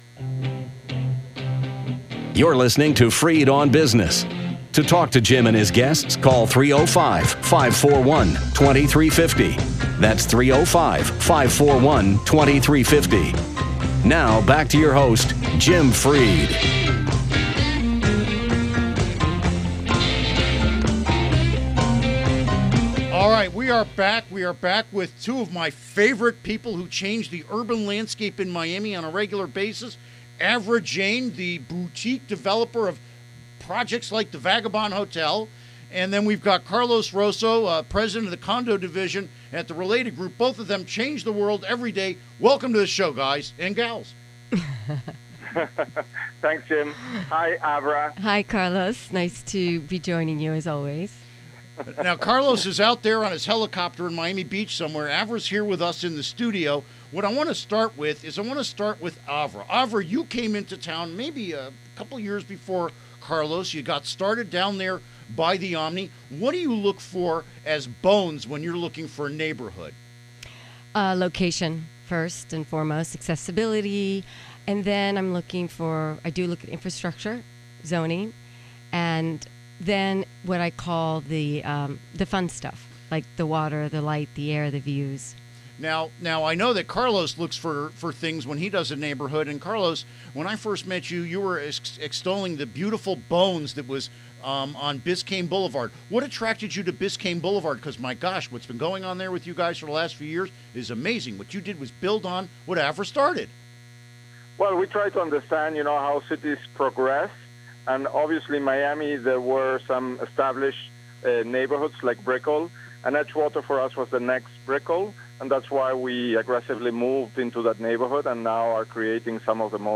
Interview Segment Episode 347: 12-17-15 (To download Part 1, right-click this link and select “Save Link As”.